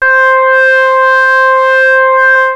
OSCAR PAD 01 5.wav